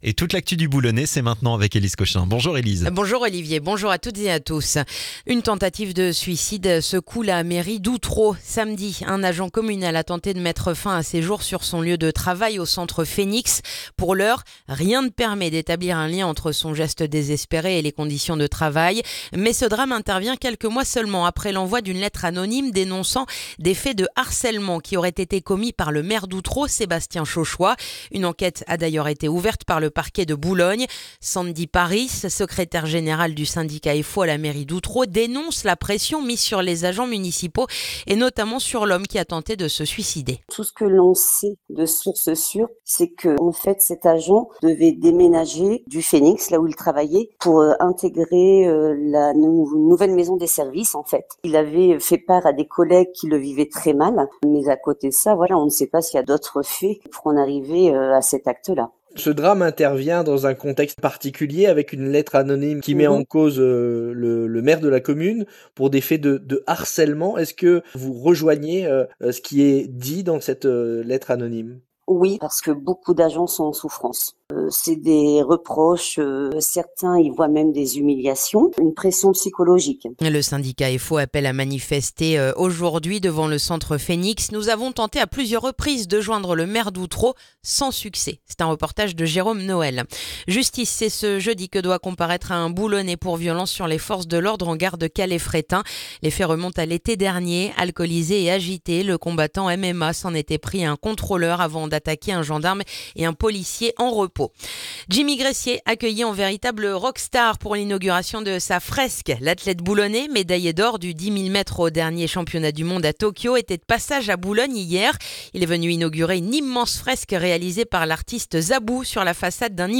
Le journal du jeudi 12 février dans le boulonnais